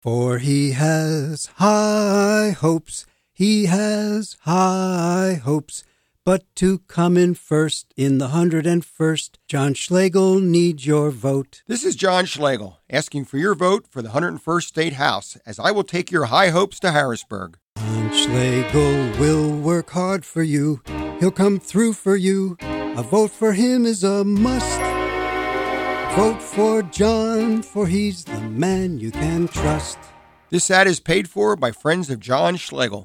Below is my campaign ad that can be heard on WLBR 1270AM and 100.1FM.